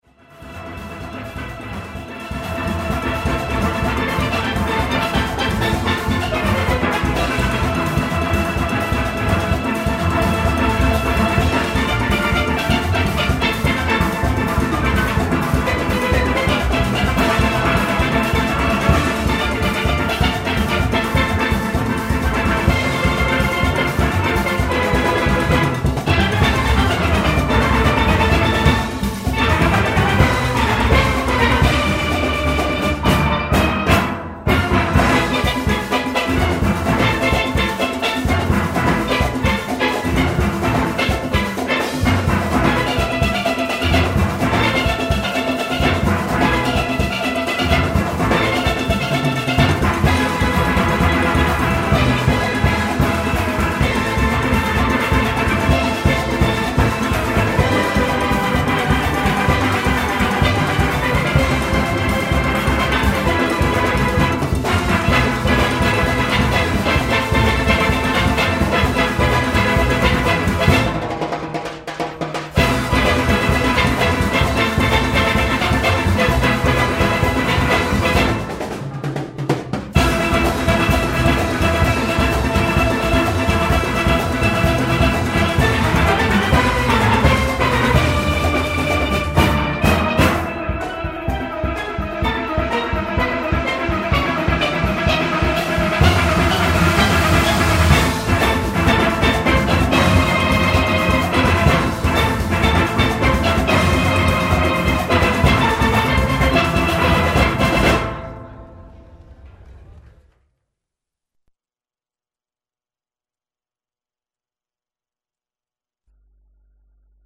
Inspelningarna är gjorda på fältet
Pan in A minor  av Lord Kitchener framförd av Renegades steelband under Panorama.
Pan in A minor  - samma som ovan, slutet av framförandet med s.k. groove och coda.